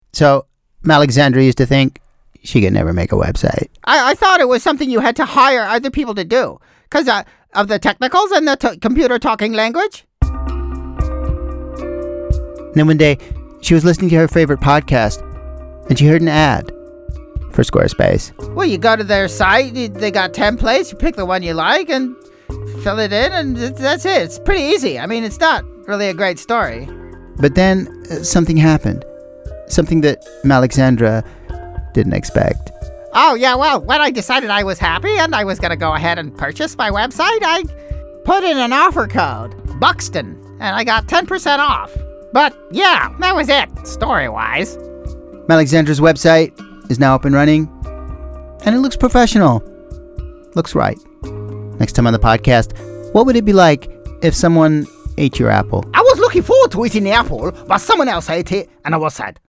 Adam Buxton’s latest Squarespace advert, which recently ran on the comedian’s long-running eponymous podcast series, is almost the perfect example of pop eating itself. The audio ad finds Buxton adopting the guise of other popular podcasts – This American Life, Jon Ronson – to sell the web-building platform, in the knowledge that his audience will totally get it.